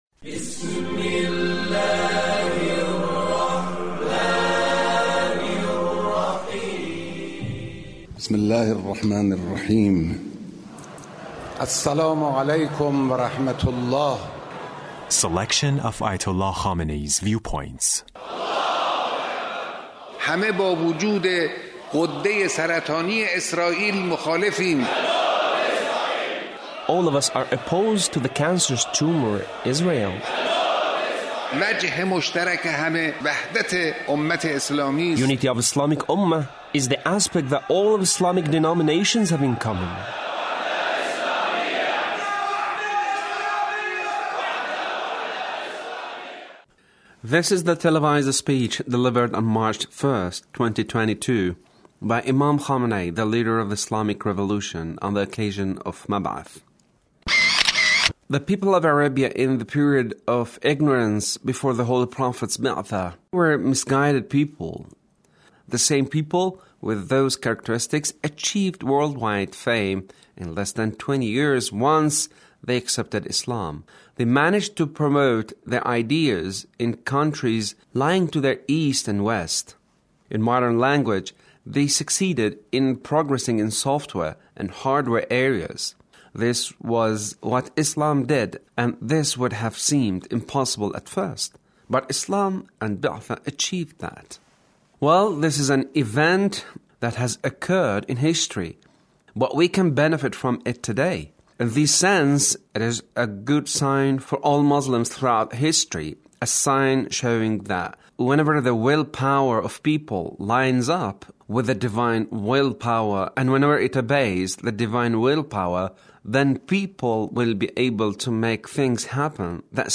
Leader's speech (1340)